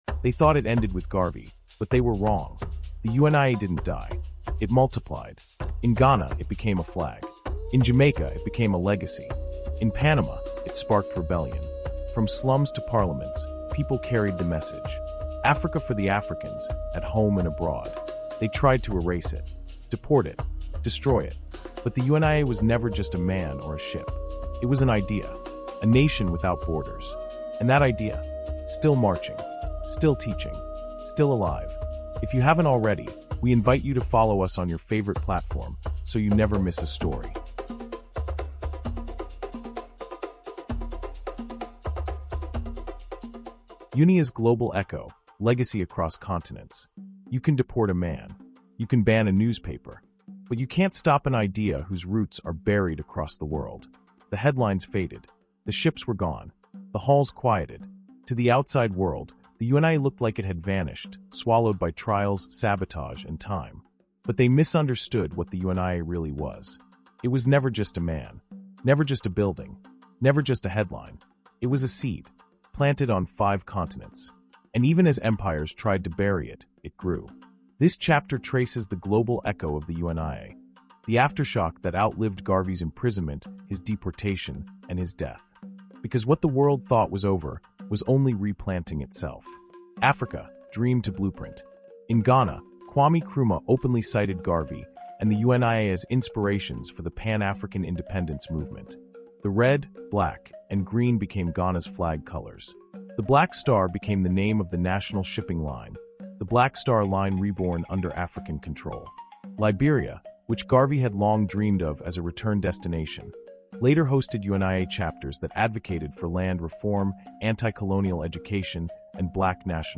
UNIA: UNIA’s Global Echo — Audiobook Documentary, Chapter 9